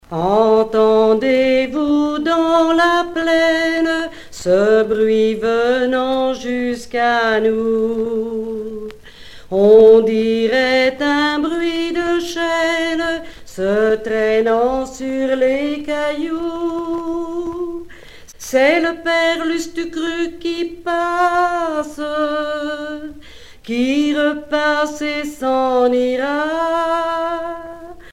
enfantine : berceuse
Genre strophique
Pièce musicale éditée